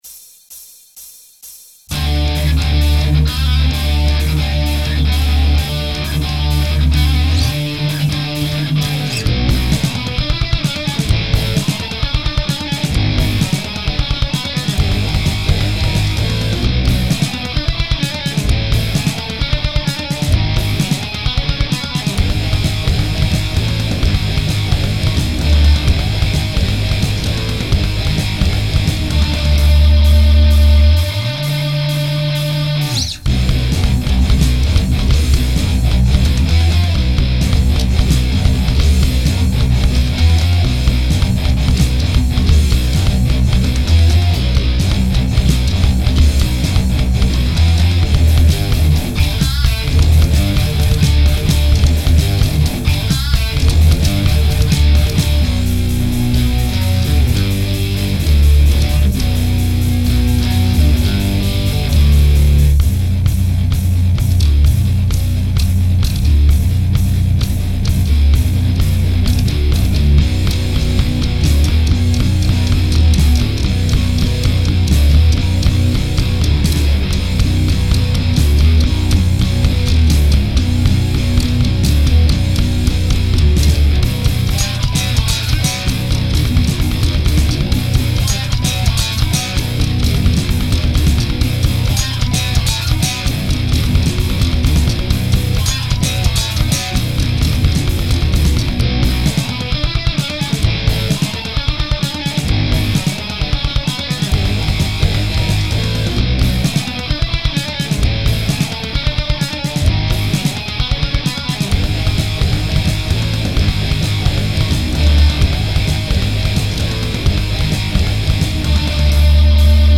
Emo shit without vocals:)